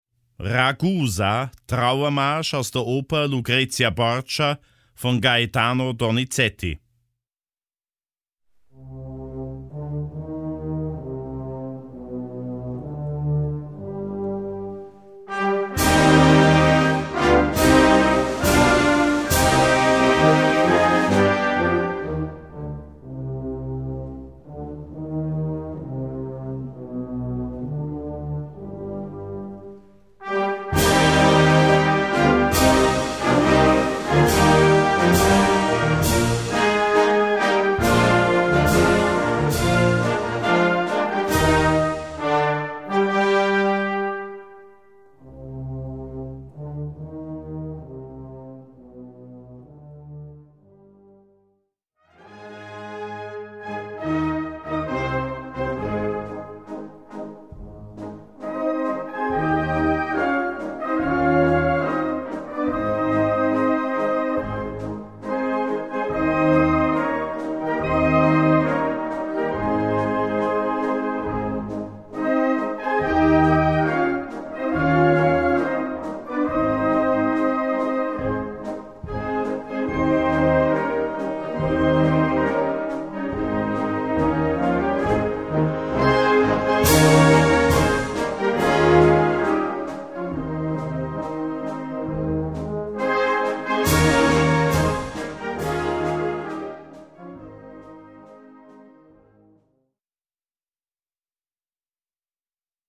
Gattung: Trauermarsch
Besetzung: Blasorchester